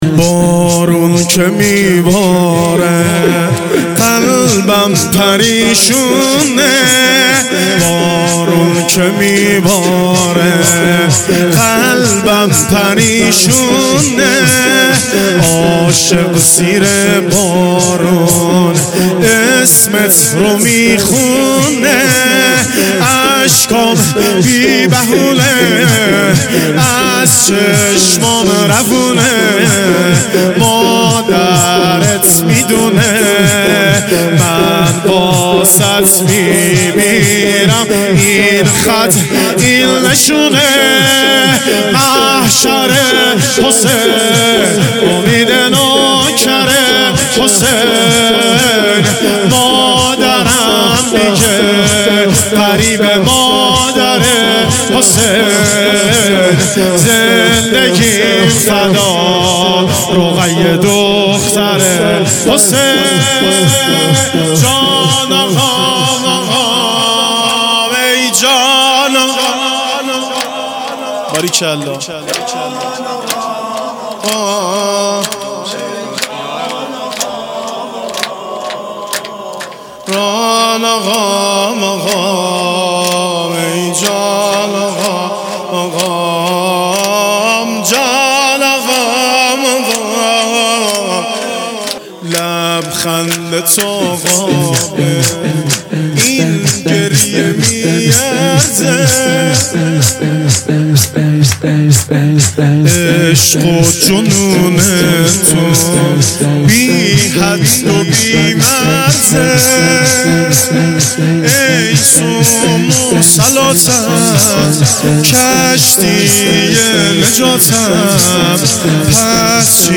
شب چهارم محرم 1400
محرم شور